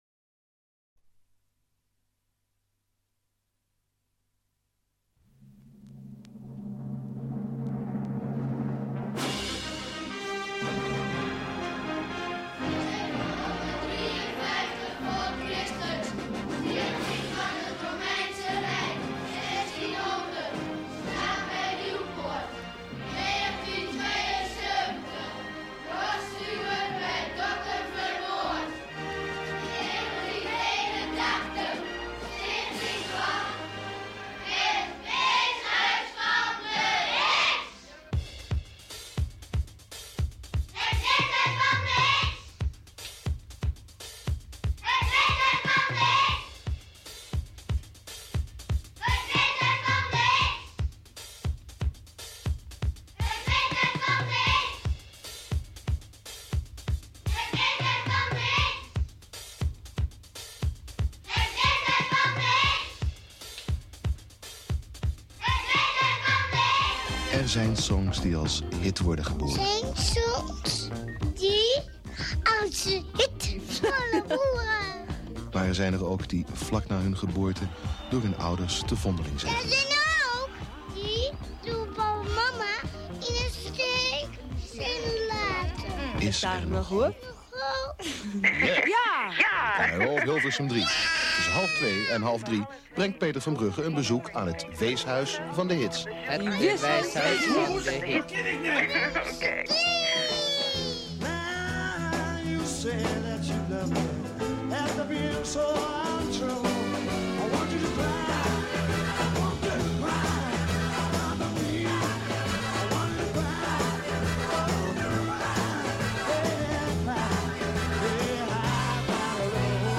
Alleen was deze aflevering opgenomen op een band die niet zo soepel liep….?